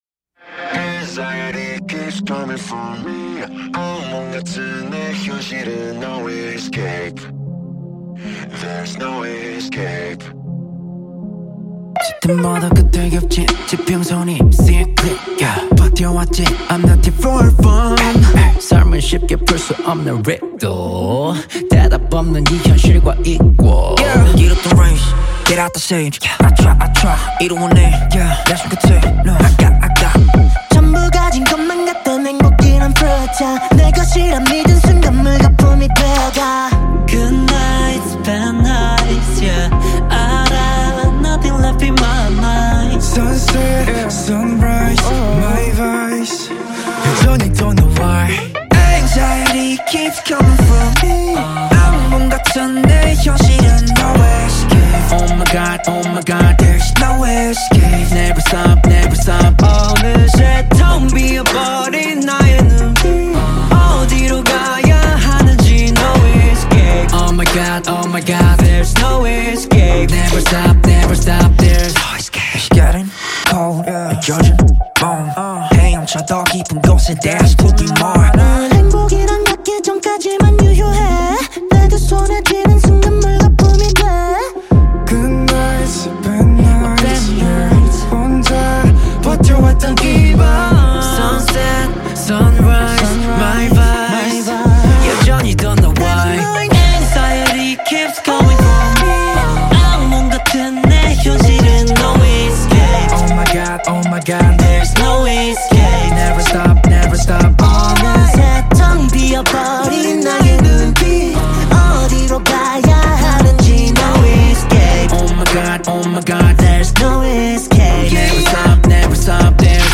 Label Dance